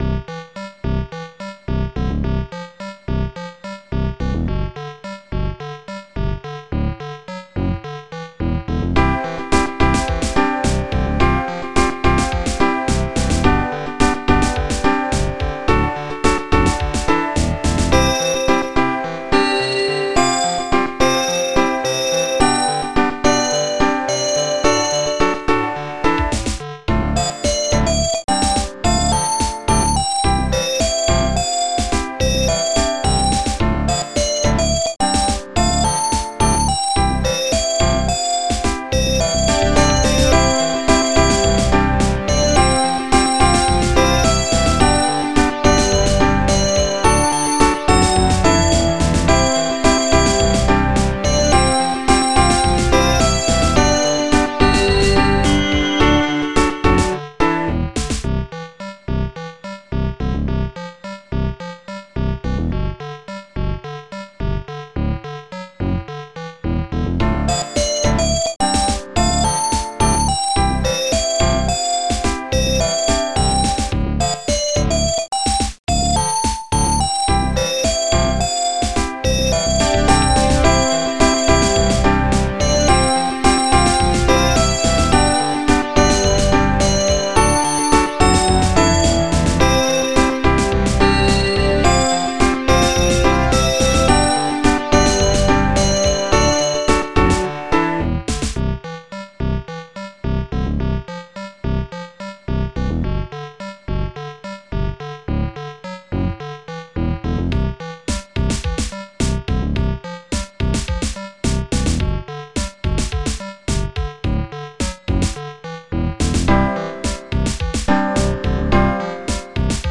usic origin: Archimedes ProTracke